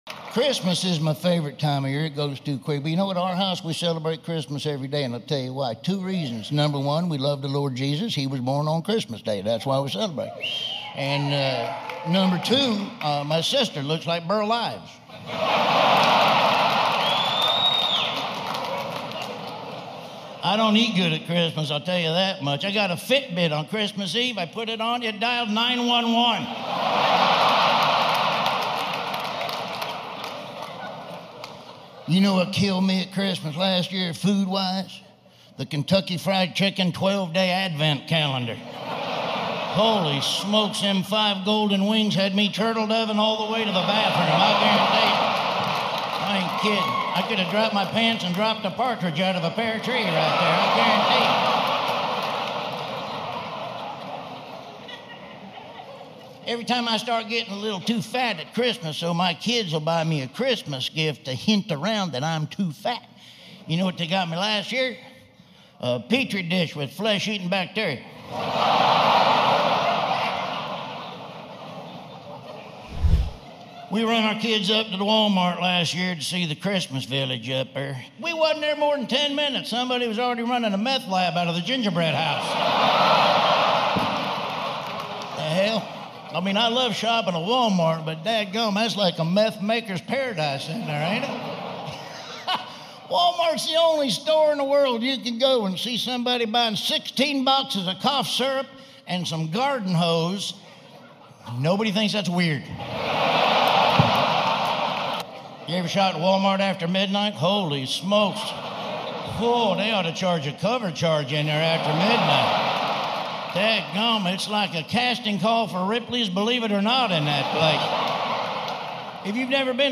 Holiday Stand-Up That Hits Better Than Eggnog
Before the first laugh even lands, you should know this: every episode of The Comedy Room places all advertisements right at the beginning, so once the show truly starts, nothing interrupts the flow, the rhythm, or the feeling.